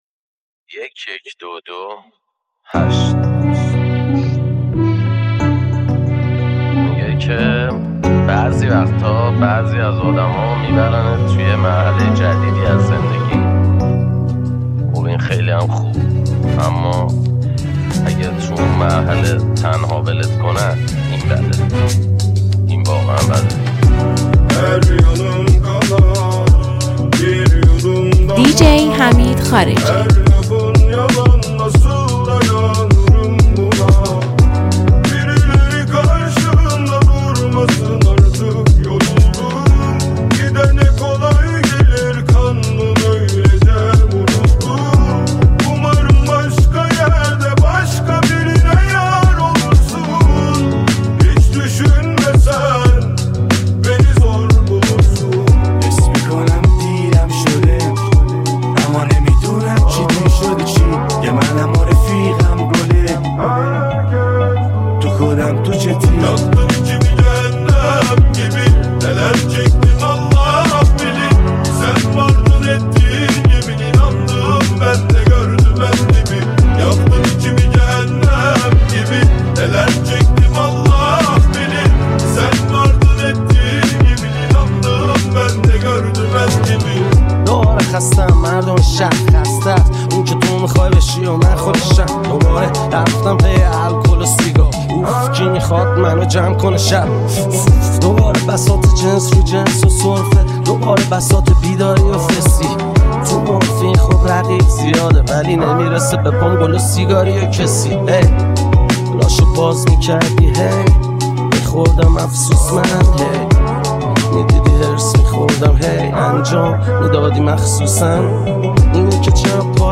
دانلود ریمیکس
میکس رپ ترکیبی